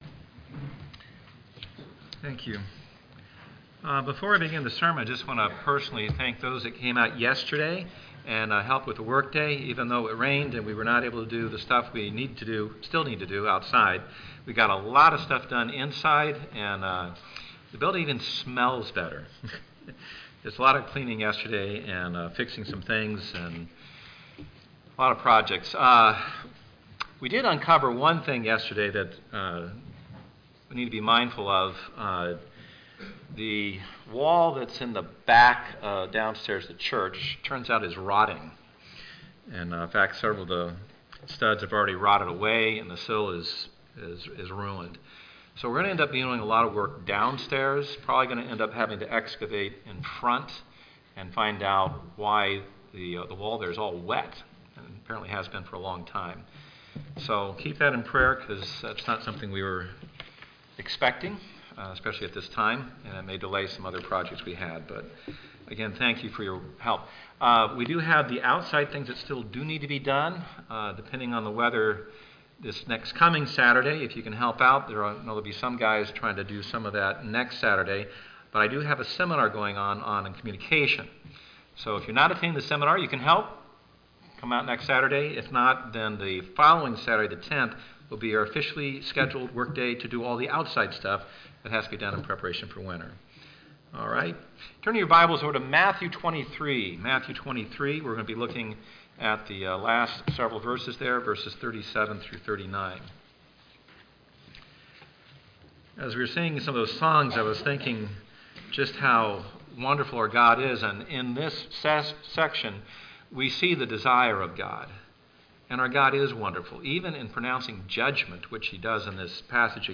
Sermons 2015